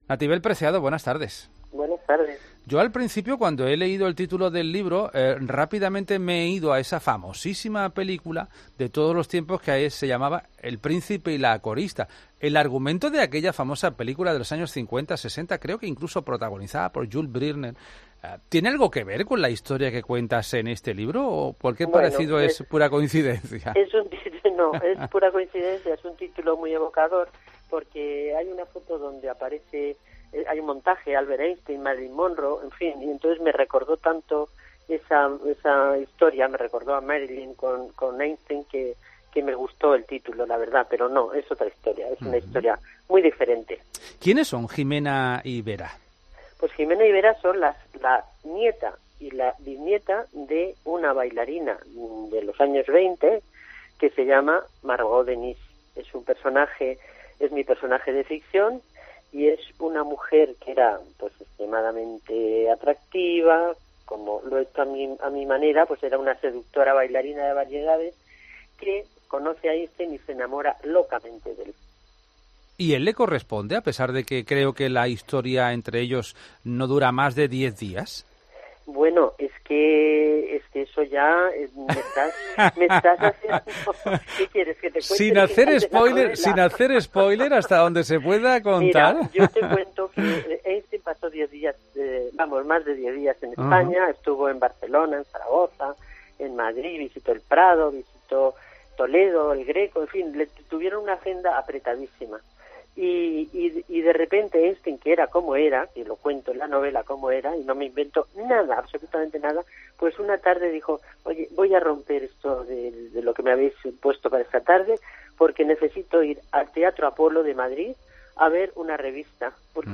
‘Se trata de un título muy evocador’ nos ha contado Nativel Preciado en COPE Málaga.